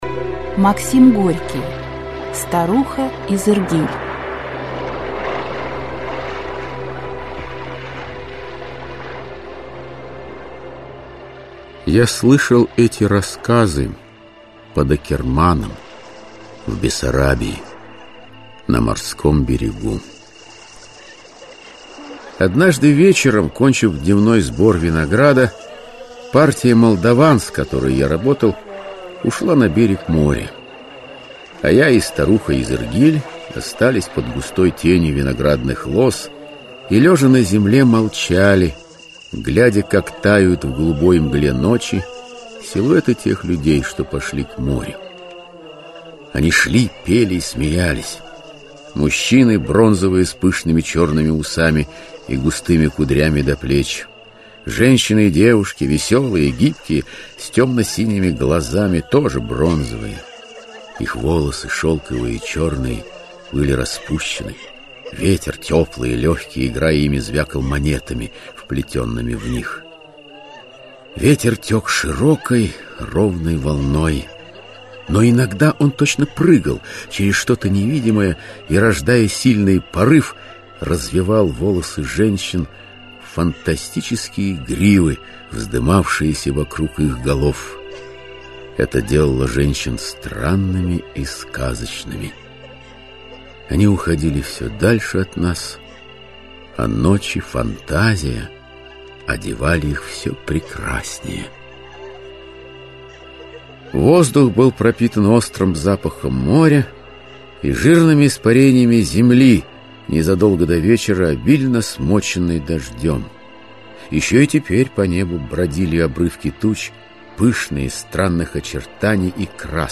Аудиоспектакль Автор Максим Горький Читает аудиокнигу Василий Бочкарев.